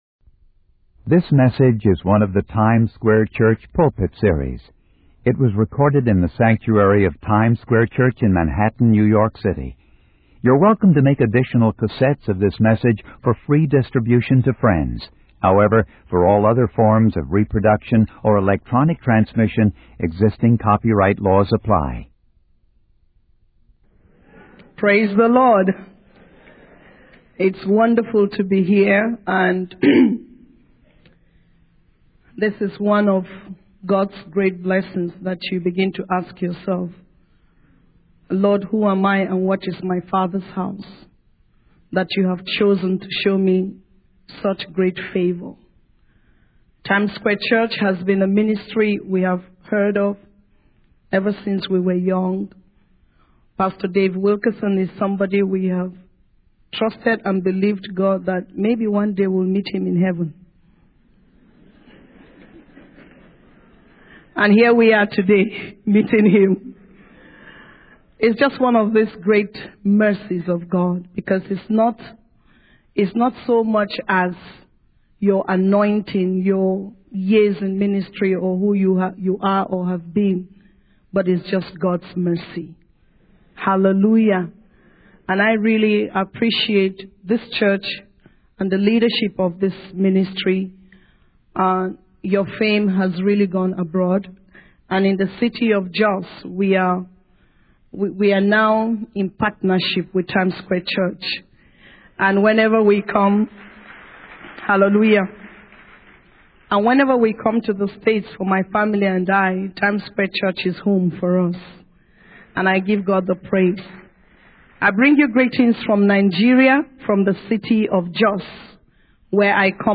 In this sermon, the speaker shares a message titled 'Anointed for Burial.' He begins by expressing his faith in God's plan for Nigeria and declares that the nation will rise again and take its rightful place in the kingdom. The speaker emphasizes the importance of being a worshipper of Jesus Christ, as it leads to experiencing the supernatural and doing things that may not be understood by others.
It was recorded in the sanctuary of Times Square Church in Manhattan, New York City.